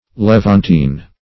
Levantine \Le*vant"ine\ (l[-e]*v[a^]nt"[i^]n or
l[e^]v"ant*[imac]n; 277), a. [F. levantin, or It. levantino.